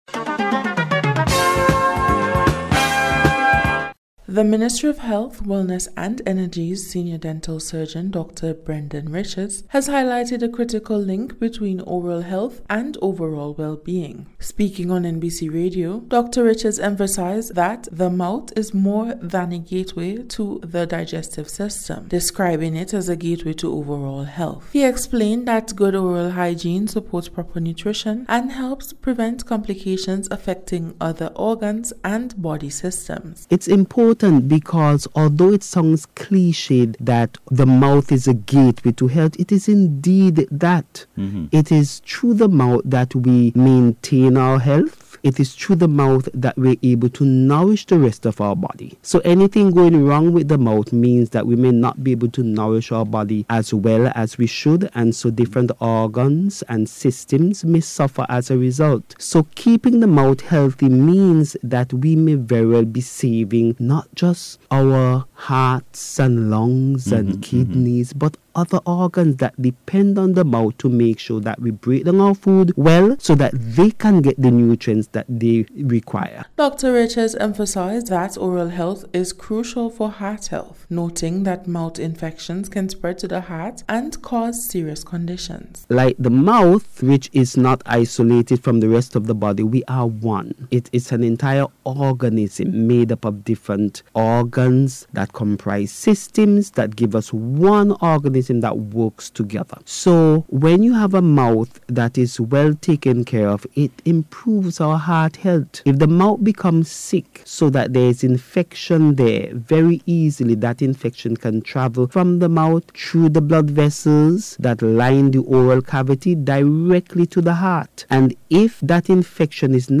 DENTAL-HEALTH-2026-REPORT.mp3